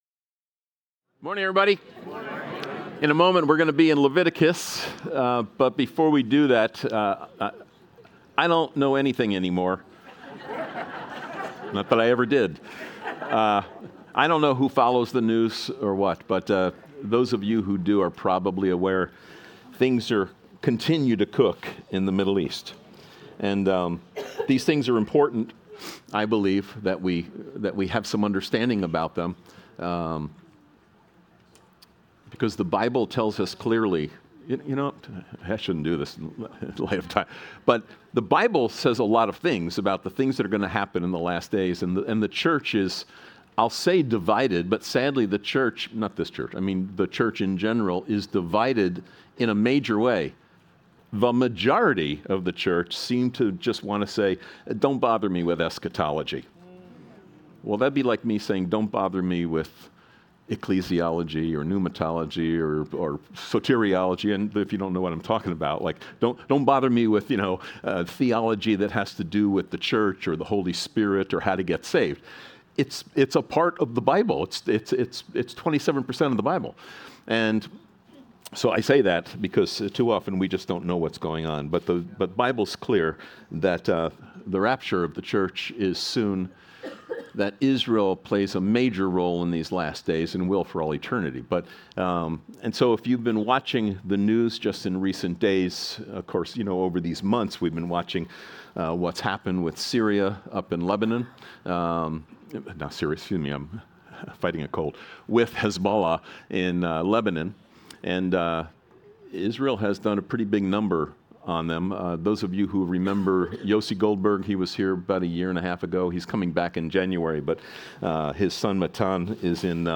Sermons | Calvary Chapel